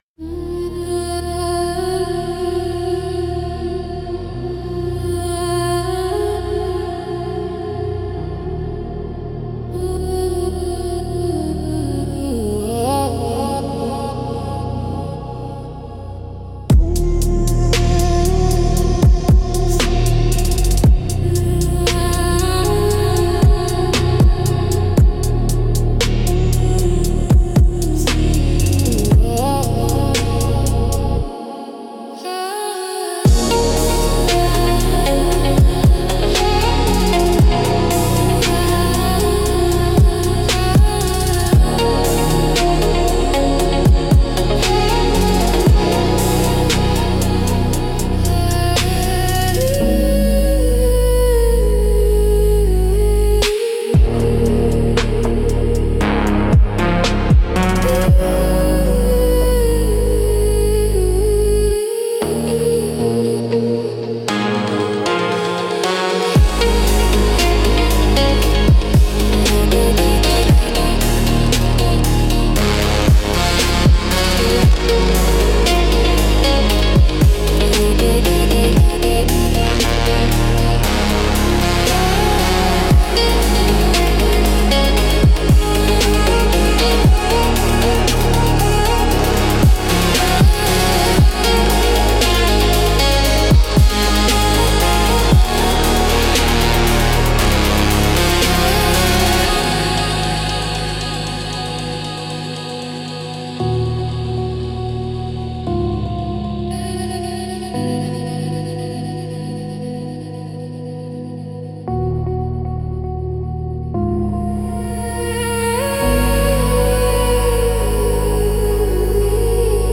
Instrumental - Velvet Depths - 5.18